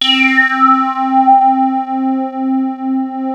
JUP 8 C5 6.wav